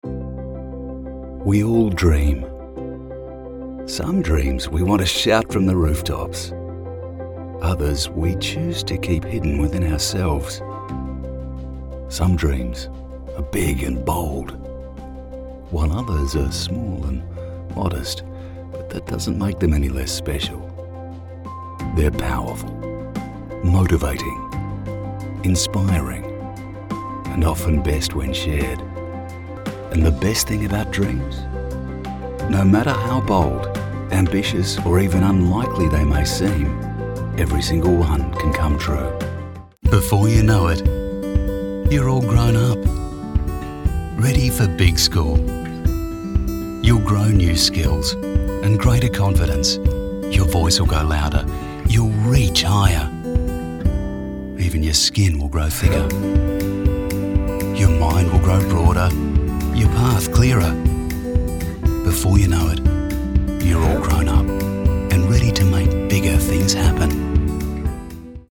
Male
English (Australian), English (Neutral - Mid Trans Atlantic)
Adult (30-50), Older Sound (50+)
Natural Speak
Natural Speaking Voice
0619Natural_Speak.mp3